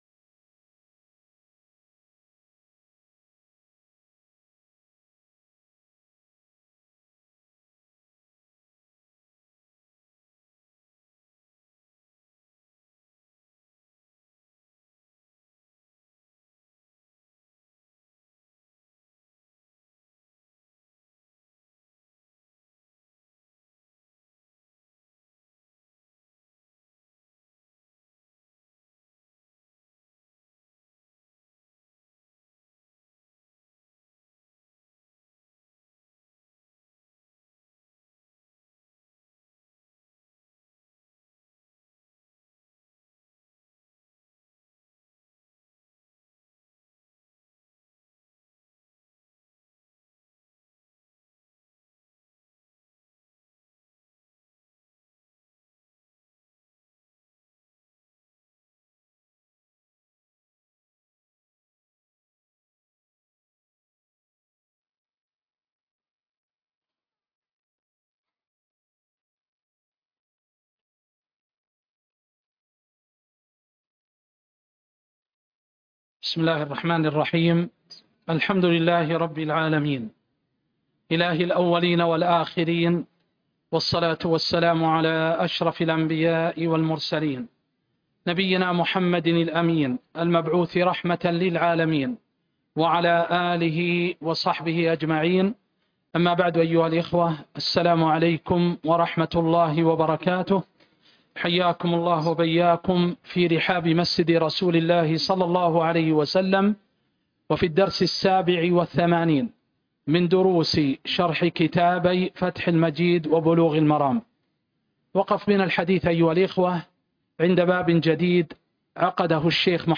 الدرس (87) شرح كتاب فتح المجيد وكتاب بلوغ المرام